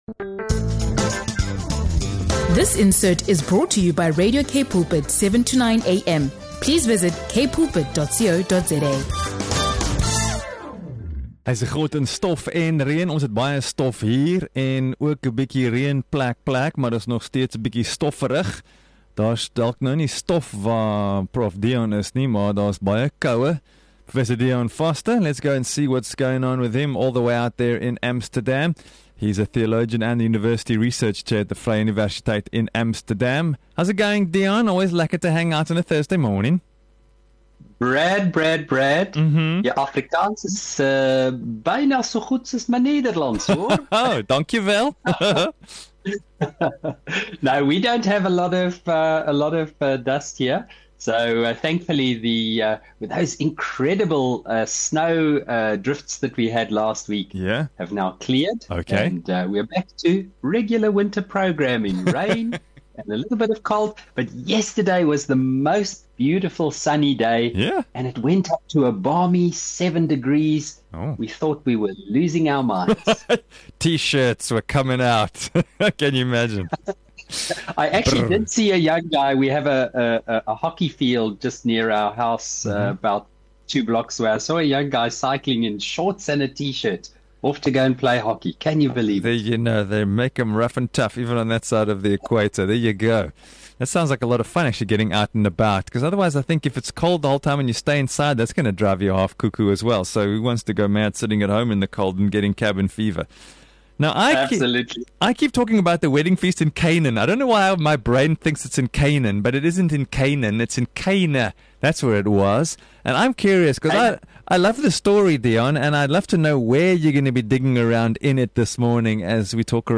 Recorded across continents—from South Africa to Amsterdam—this episode explores how Jesus chose an ordinary wedding feast, not a temple or sermon, to reveal divine power by turning water into wine.
This podcast invites listeners to reconsider where and how God works today: in small acts of obedience, in everyday moments, and through ordinary people. A warm, insightful discussion on faith, humility, hospitality, and how God transforms the ordinary into something extraordinary.